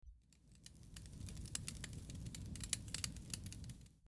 Fire Crackling
fire_crackling.mp3